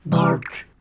bark.mp3